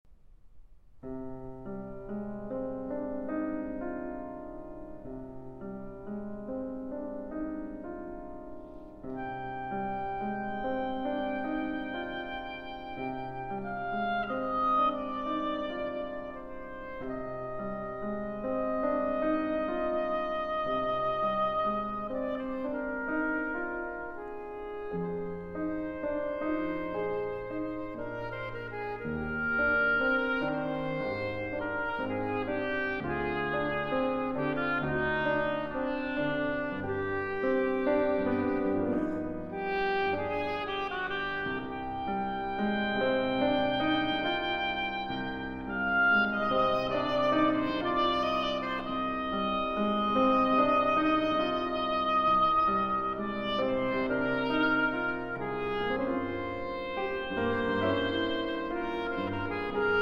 Oboe
Piano